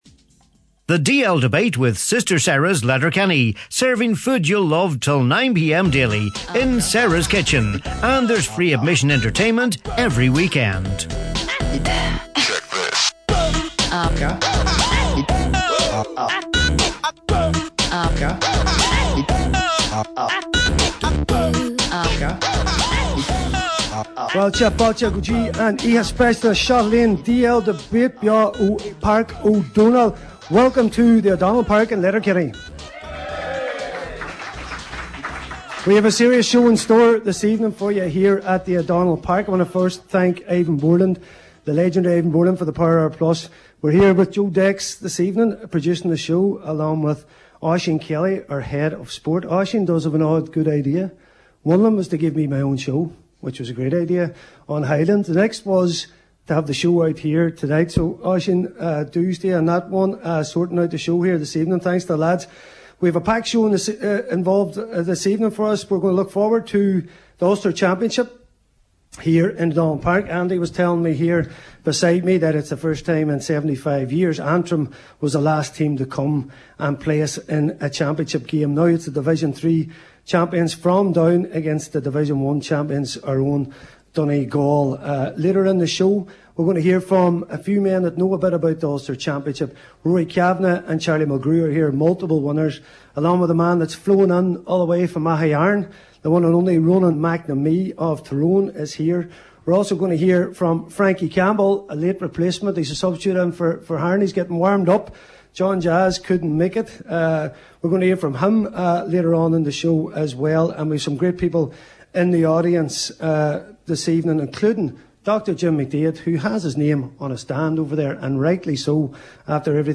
The DL Debate – LIVE from St Eunans